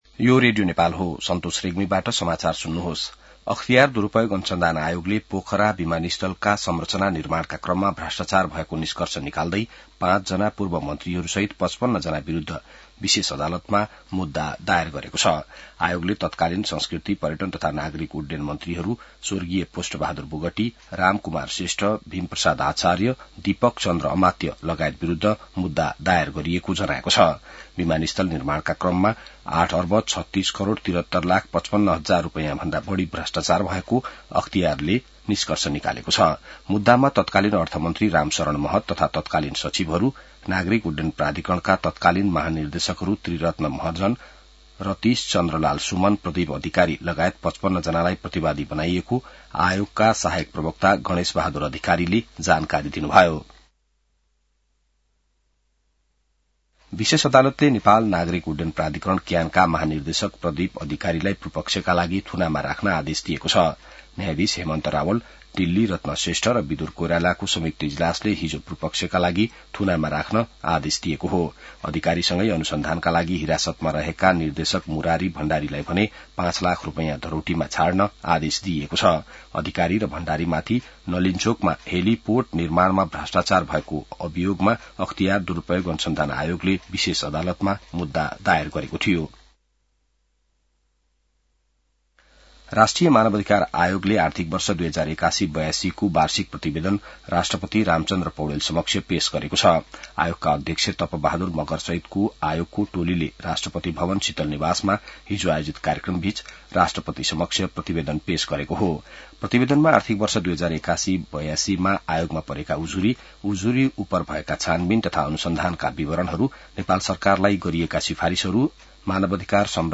बिहान ६ बजेको नेपाली समाचार : २२ मंसिर , २०८२